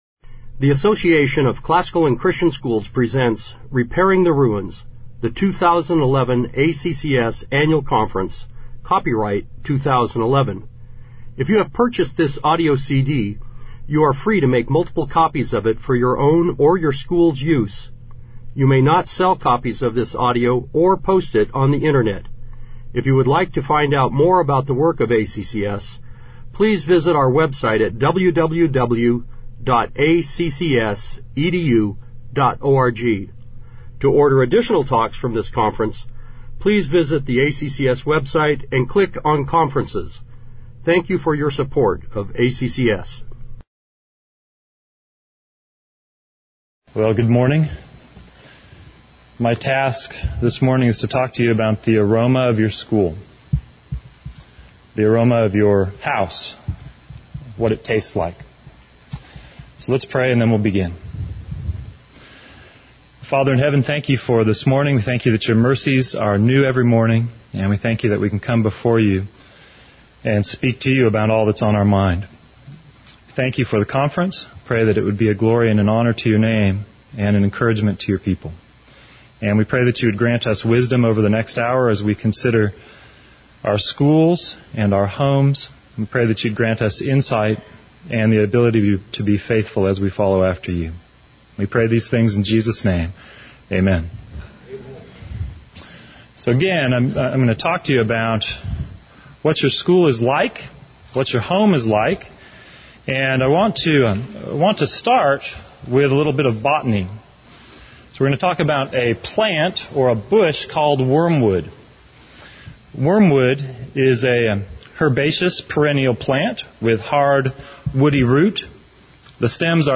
2011 Plenary Talk | 0:57:02 | All Grade Levels, Virtue, Character, Discipline